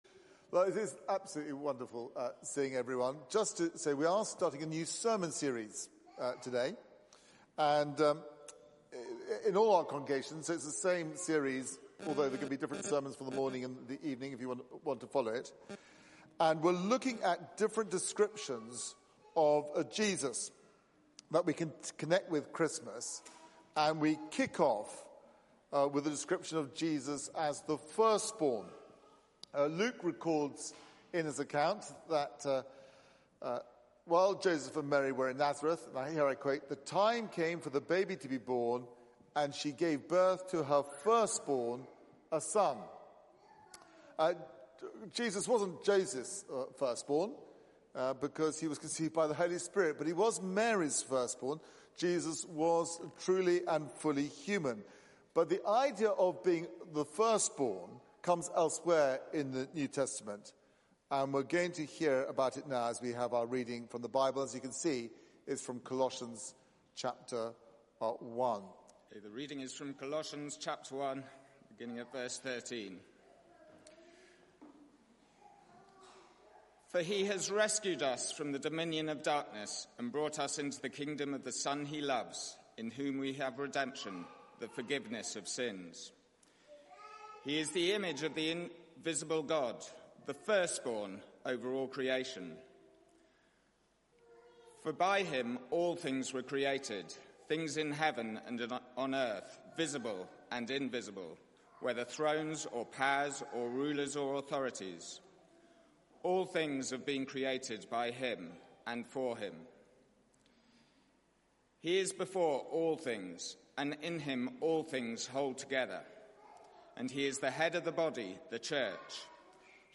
Media for 9:15am Service on Sun 06th Dec 2020
Theme: The firstborn Sermon